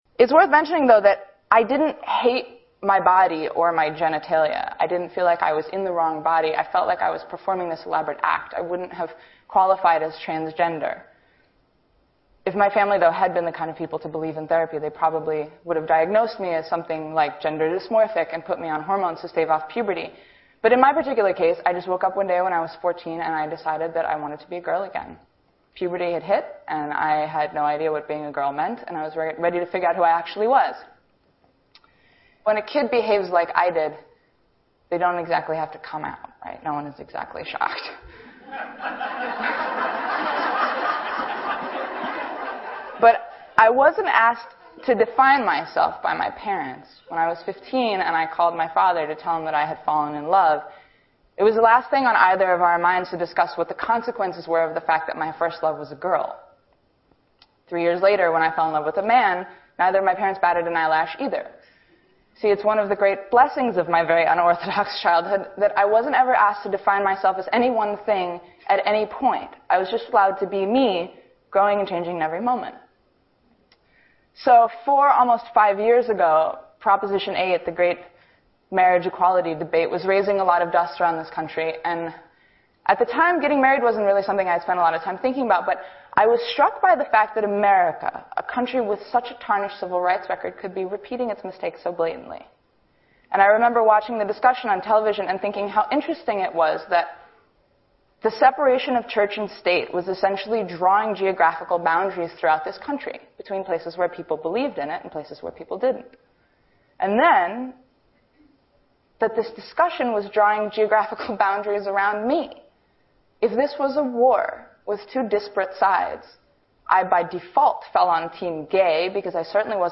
TED演讲:同性恋和异性恋(2) 听力文件下载—在线英语听力室